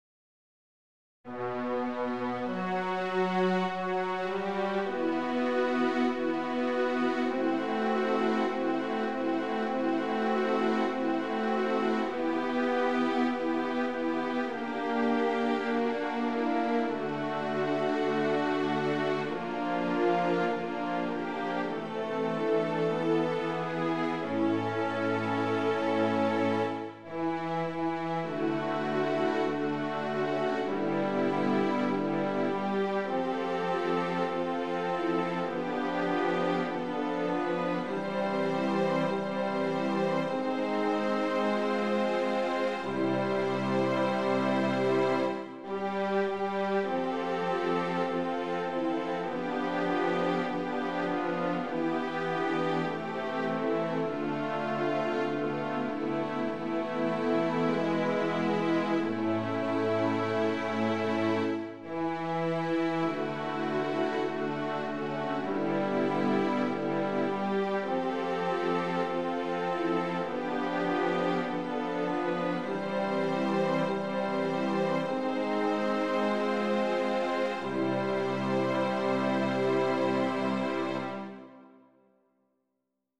Number of voices: 4vv Voicing: SABB Genre: Sacred
Language: Latin Instruments: A cappella